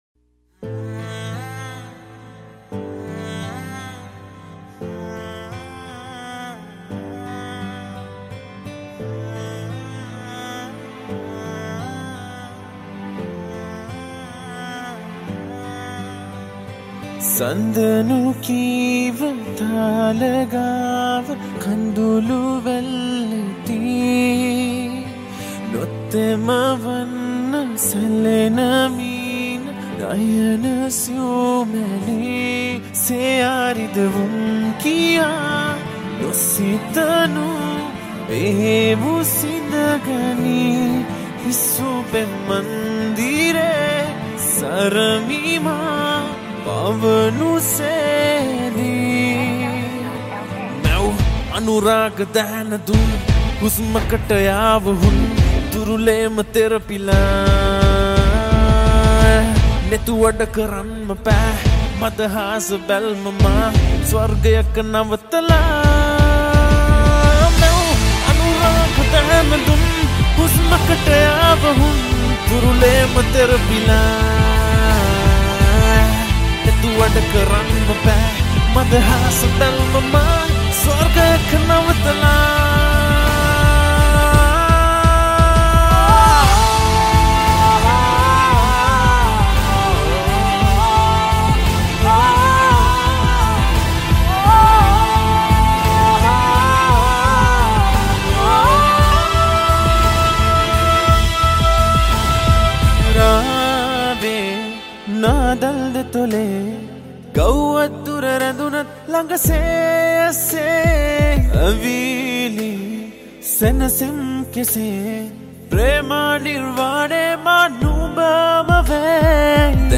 Vocal
Sinhala Version